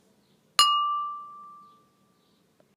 pling.mp3